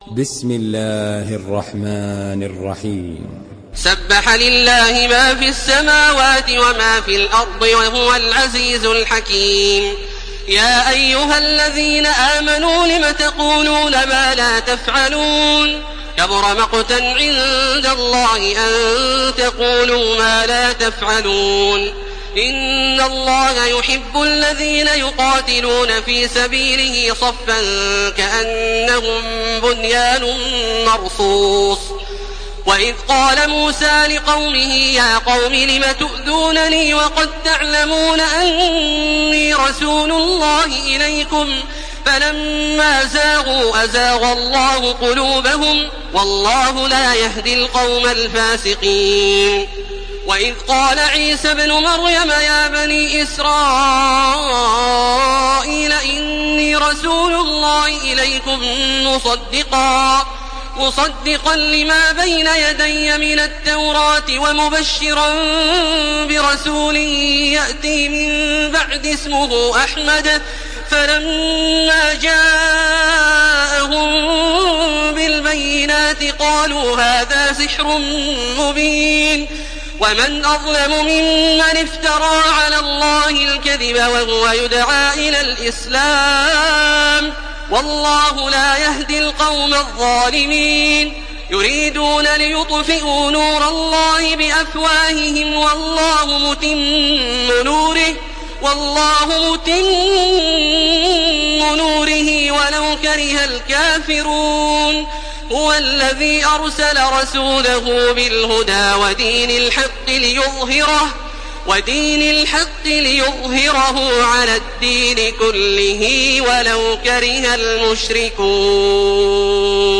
Surah Saf MP3 by Makkah Taraweeh 1431 in Hafs An Asim narration.
Murattal